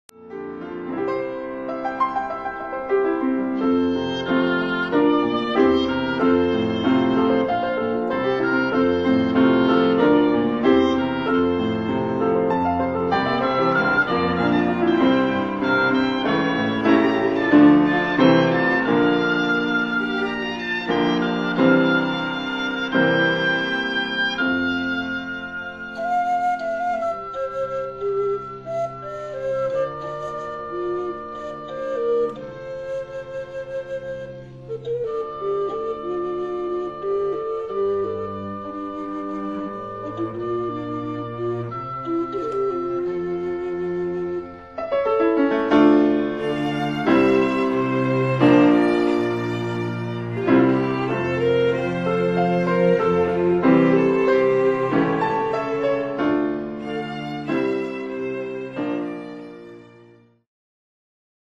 音楽ファイルは WMA 32 Kbps モノラルです。
Flute、Oboe、Recorder、Viola、Cello、Piano
（253,576 bytes） なつかしい人を思い出した時に感じる、嬉しさ、そして切なさ。